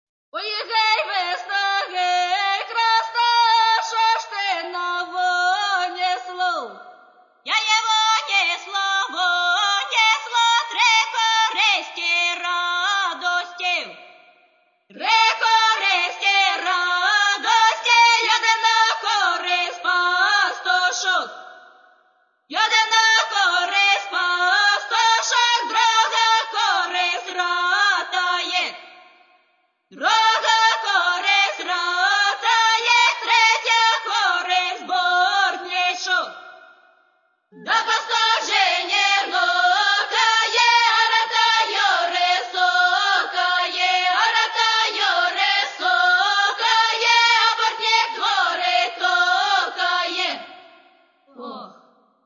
Каталог -> Народна -> Солоспіви та хори
Власне, не просто пісенну, а магічно-обрядову.